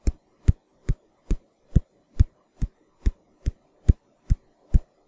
描述：用嘴记录
Tag: 慢跑 慢跑 快速步行